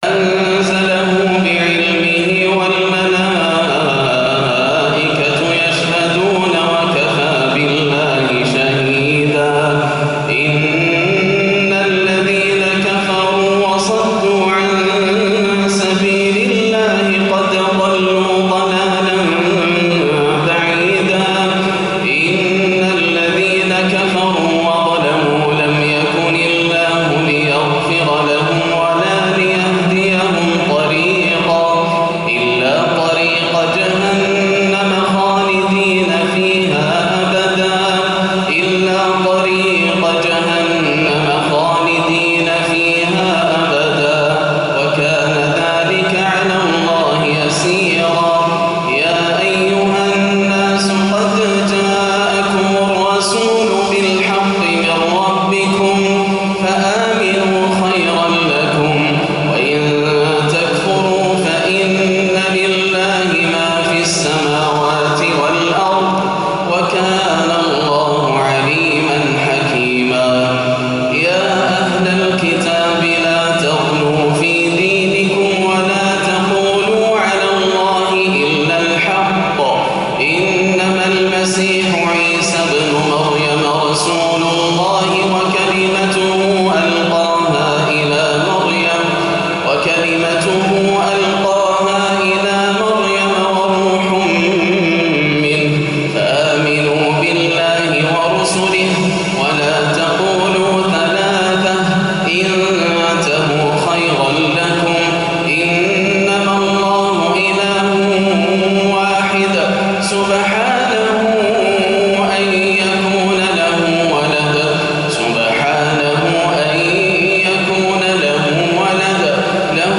فجر الأحد 8-8-1437هـ أواخر سورة النساء > عام 1437 > الفروض - تلاوات ياسر الدوسري